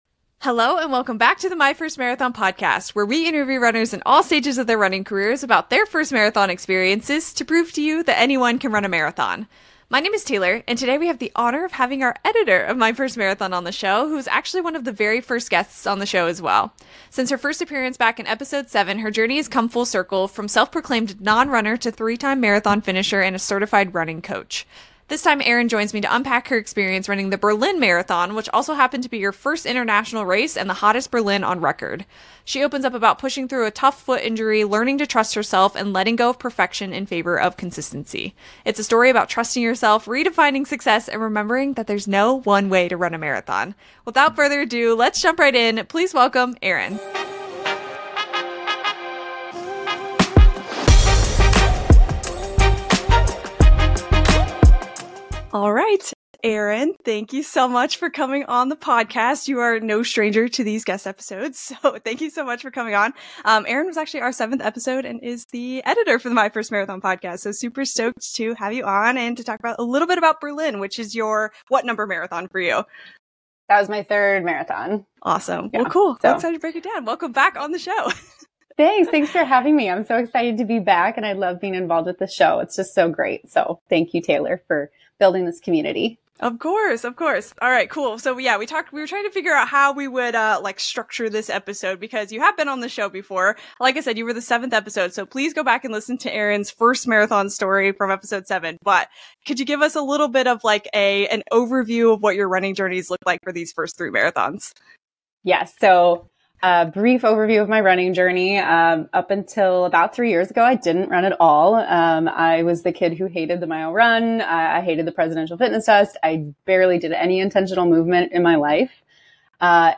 It’s an honest, funny, and full-circle conversation about growth, grit, and showing up anyway.